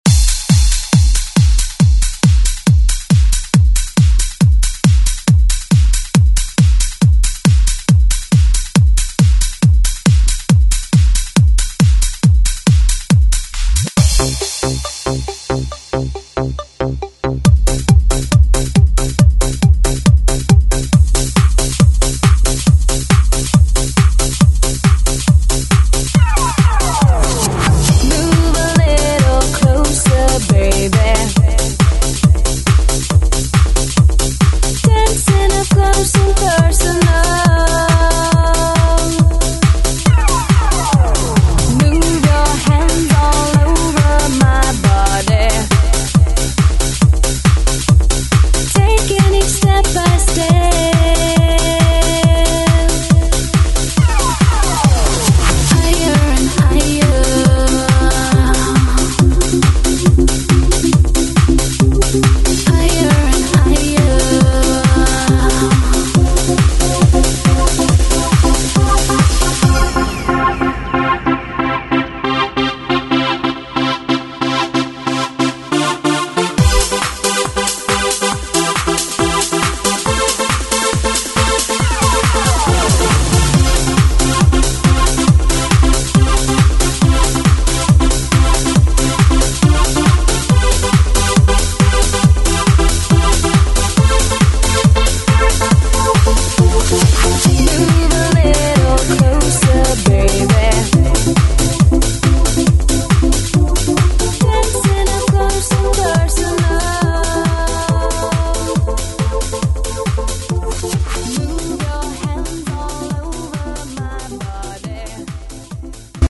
Extended Club ReWork
125 bpm
Genre: 70's